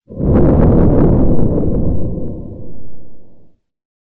ドラゴン・魔獣・怪物
龍のうなり
dragon_growl.mp3